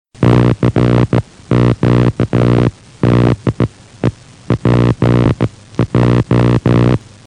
매시 무선 방송국(스테이션 "PJ")에서 스파크 갭 송신기를 사용하여 모스 부호(CQ DE PJ)를 전송하는 시연